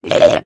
Minecraft Version Minecraft Version 1.21.5 Latest Release | Latest Snapshot 1.21.5 / assets / minecraft / sounds / entity / shulker / ambient7.ogg Compare With Compare With Latest Release | Latest Snapshot